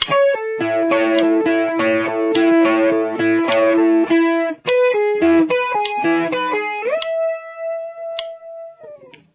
Guitar Solo
Dual Guitar
Guitar 1